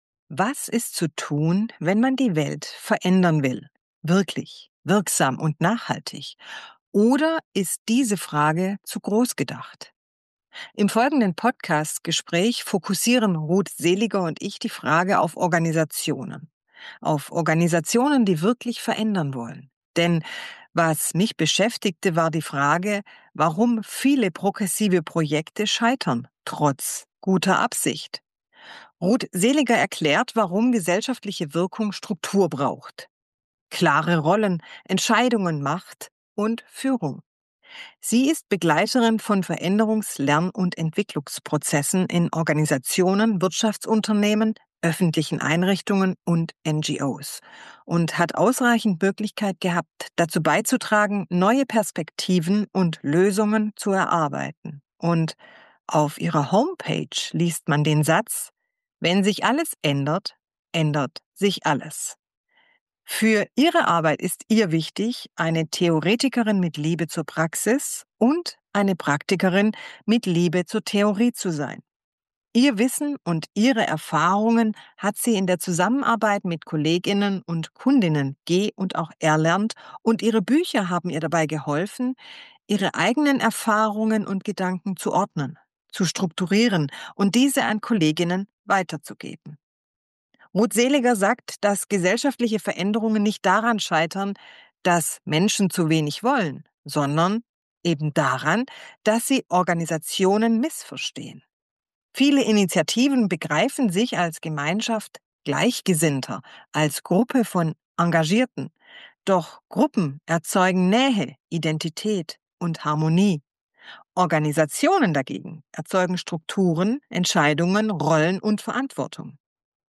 Ein Gespräch über Organisation, Macht, Harmonie, Konsens und echte Veränderung.